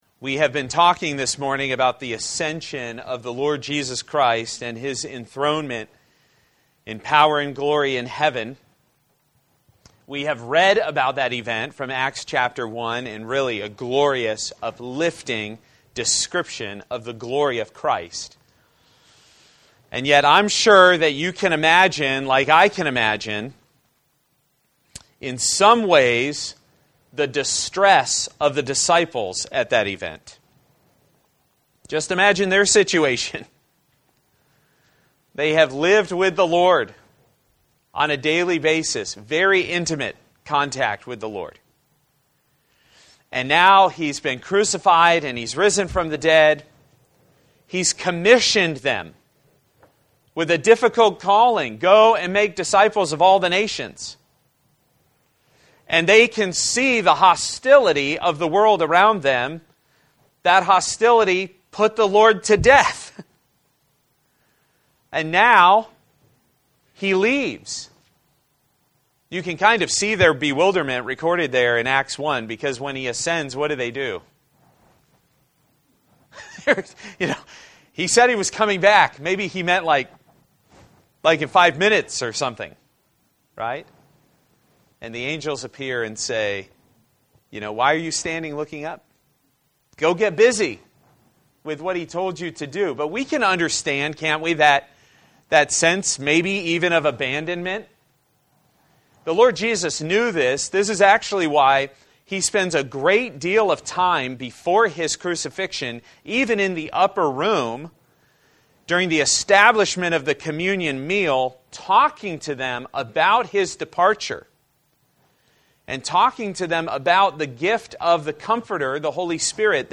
A message from the series "He Was Raised." I Corinthians 15:54-58